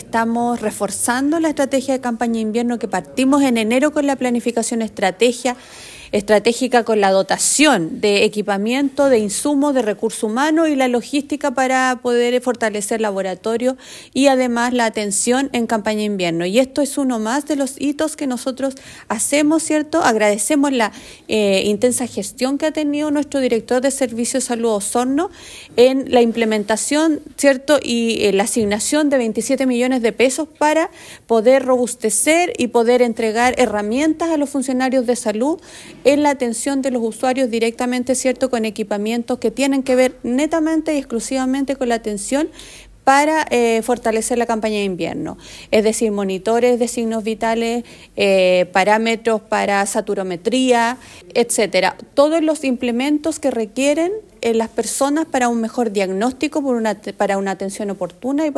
La Seremi de Salud, Karin Solís Hinojosa indicó que esto es parte de la planificación estratégica de la Campaña de Invierno 2024, para poder reforzar la red asistencial.